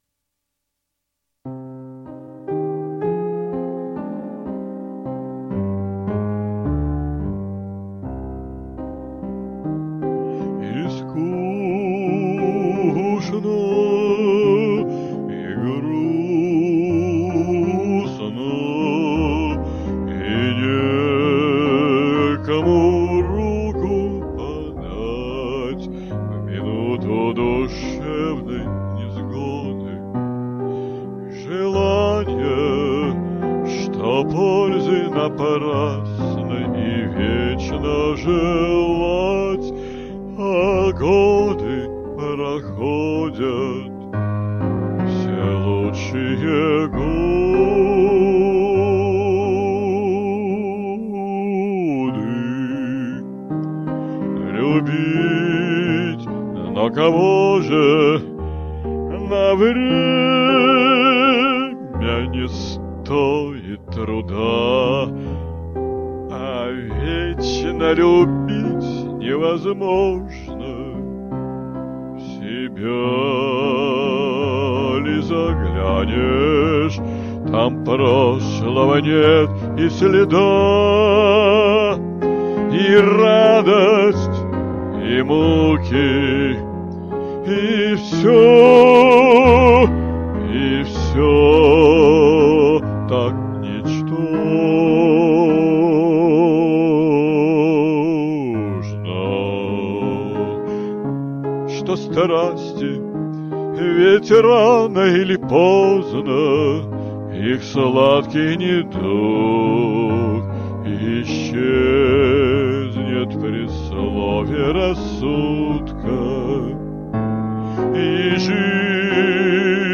«Русский классический романс»